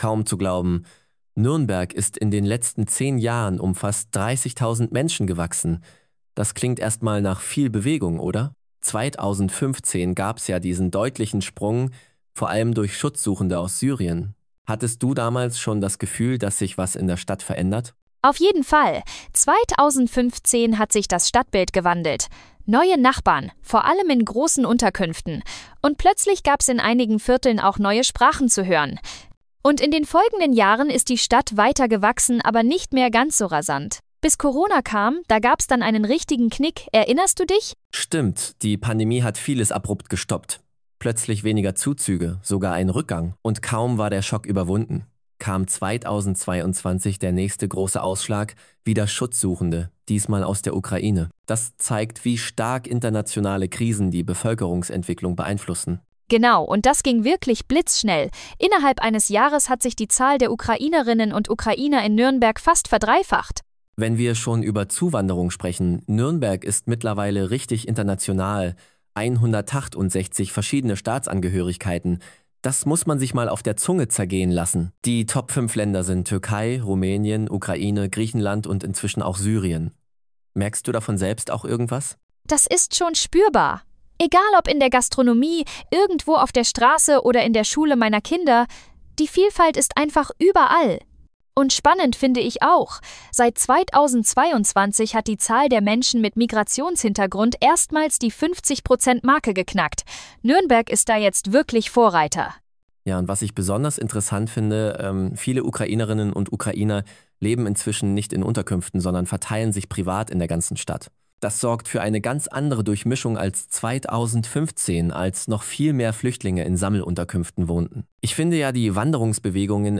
SuS-Bericht als Kurz-Podcast (deutsch, generiert mit KI)
m558_ki_podcast_nuernbergs_bevoelkerungsentwicklung.mp3